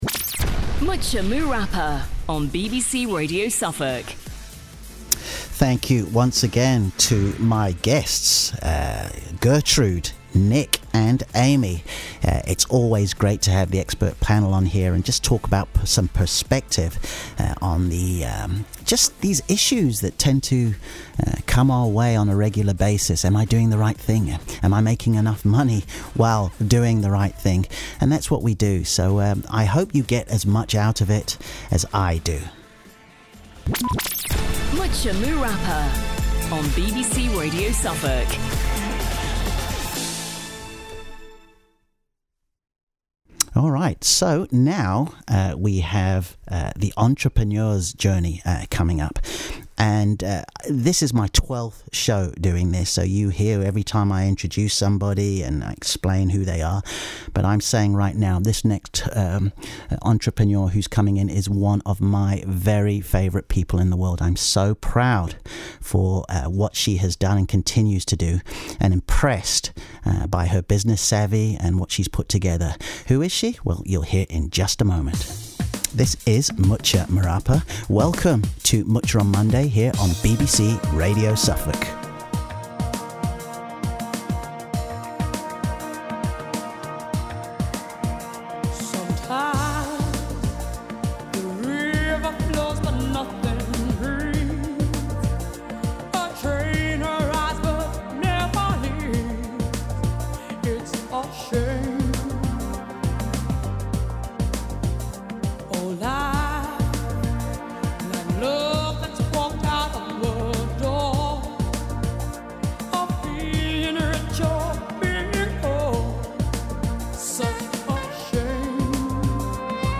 BBC Radio Suffolk Interview
Selecting five of my favourite songs past and present was also great fun. The time spent was mainly interacting and sharing how I came about starting my own business back in 2012.